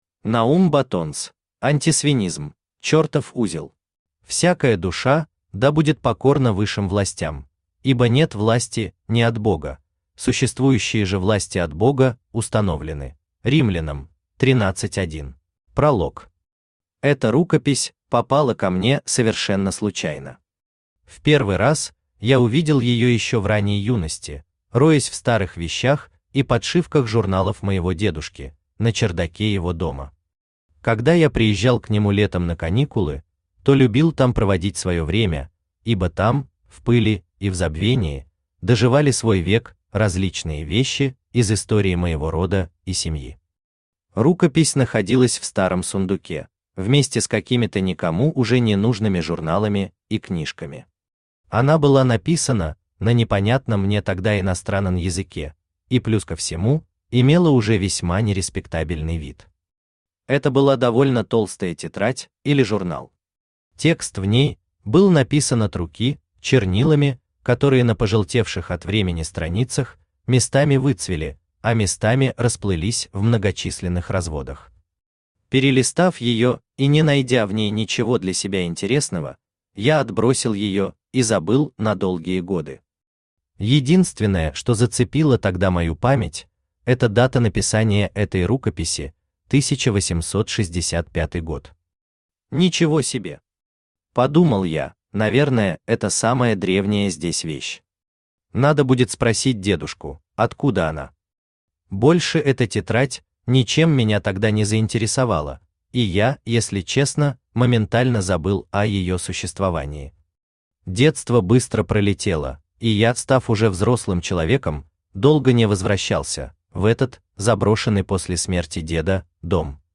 Аудиокнига Антисвинизм. Чёртов узел | Библиотека аудиокниг
Чёртов узел Автор Наум Баттонс Читает аудиокнигу Авточтец ЛитРес.